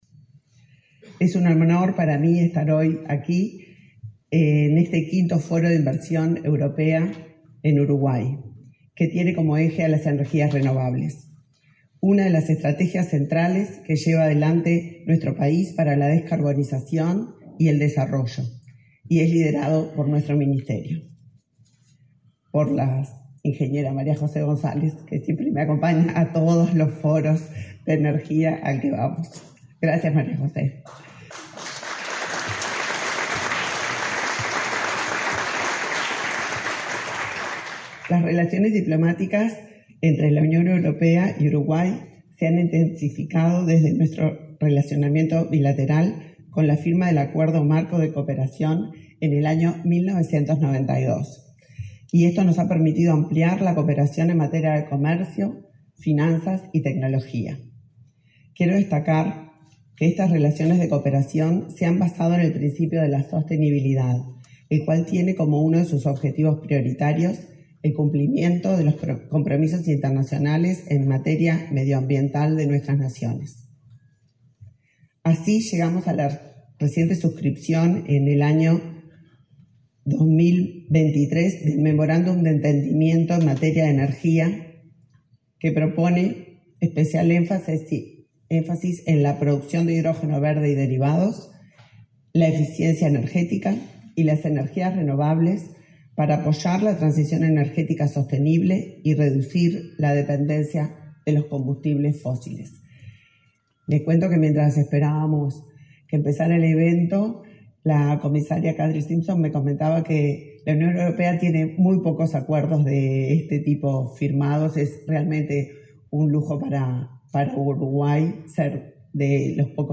Palabras de la ministra de Industria, Elisa Facio
Palabras de la ministra de Industria, Elisa Facio 30/09/2024 Compartir Facebook X Copiar enlace WhatsApp LinkedIn La ministra de Industria, Elisa Facio, participó en la apertura del V Foro de Inversión Europea en Uruguay: Renovables en Marcha. El evento se realiza este lunes 30 en la Torre de las Telecomunicaciones.